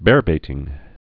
(bârbātĭng)